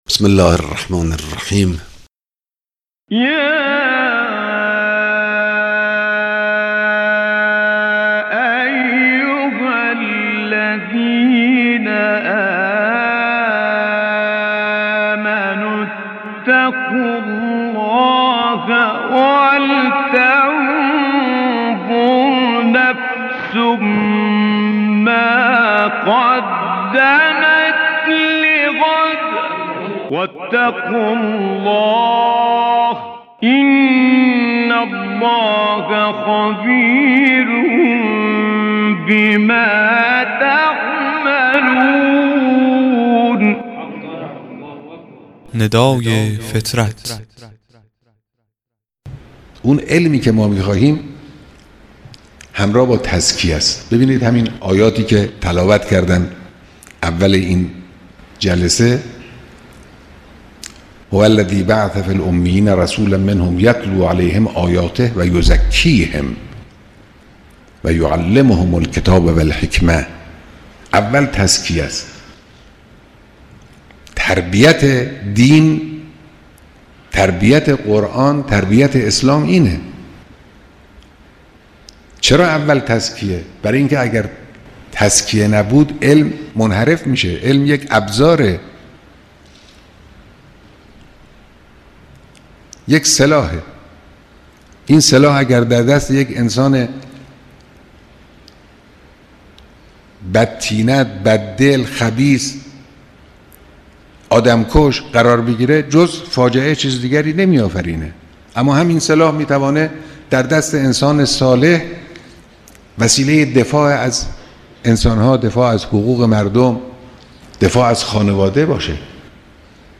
قطعه صوتی کوتاه و زیبا از امام خامنه ای